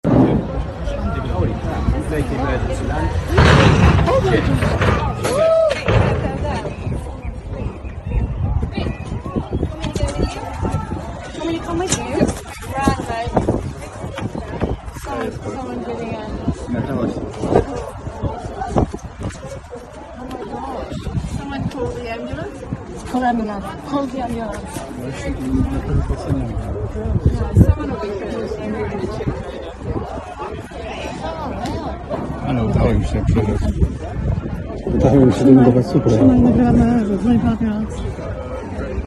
Avioneta aterrizaje de emergencia sound effects free download